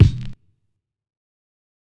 006_Lo-Fi Compressed Kick.wav